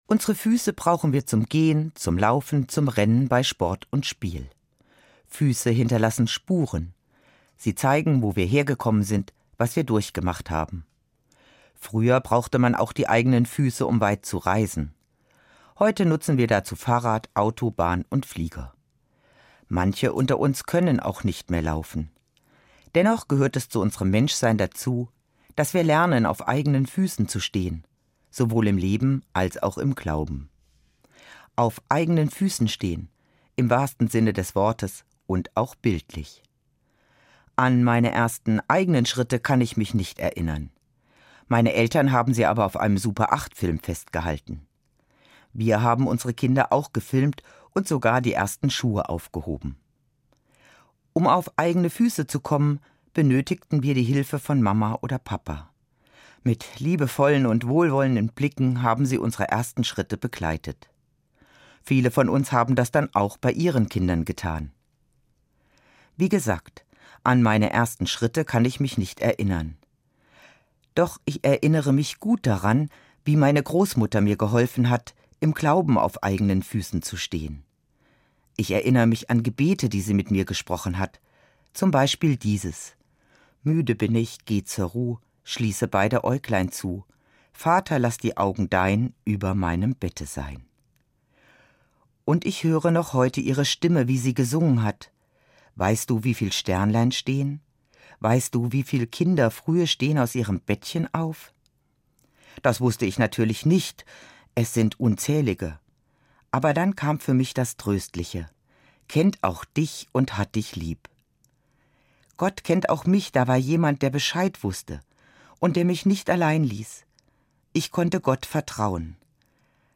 MORGENFEIER